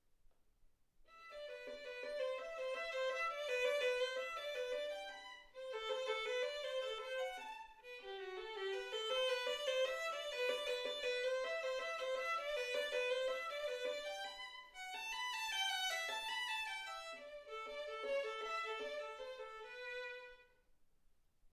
Hegedű etűdök
Classical music